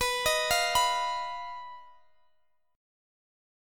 Listen to BMb5 strummed